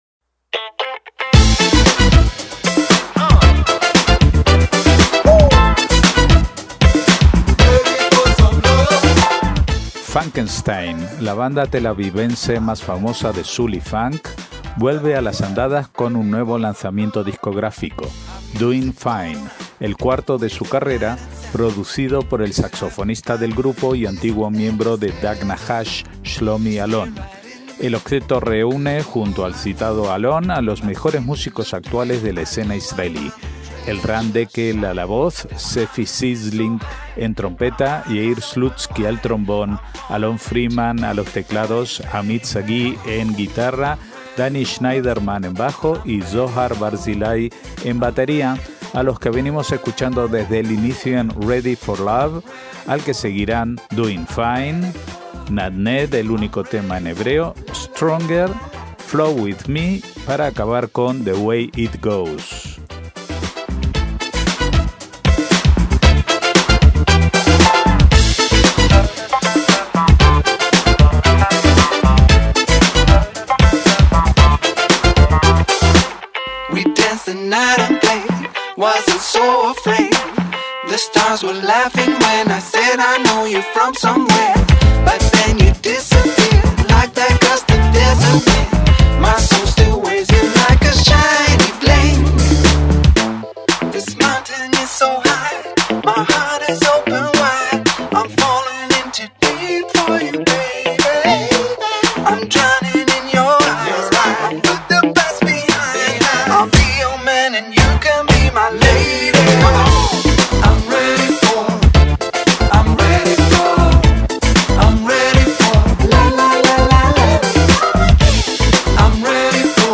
MÚSICA ISRAELÍ
soul y funk
trompeta
trombón
teclados
guitarra
bajo
batería